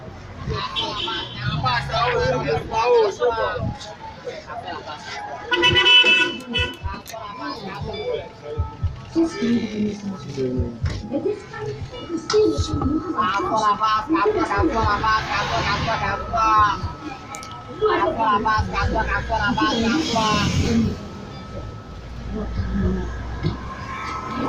승객을 부르는 차장